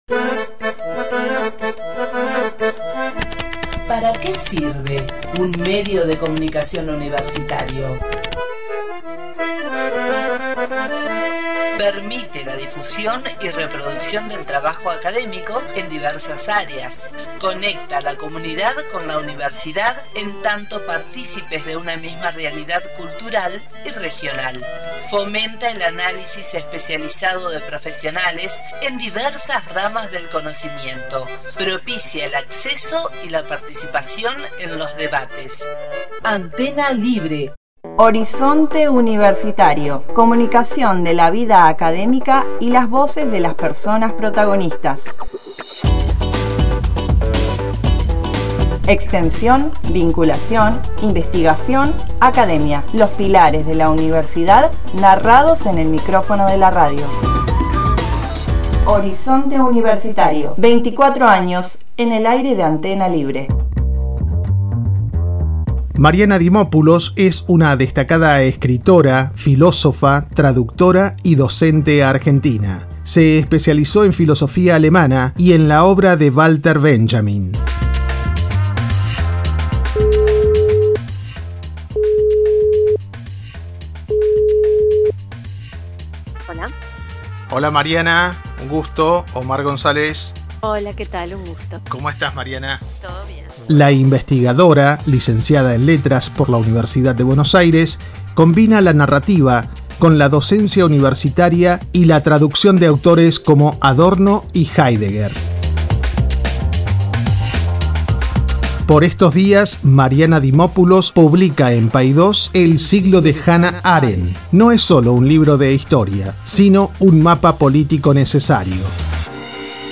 En una charla necesaria para aportar a la filosofía política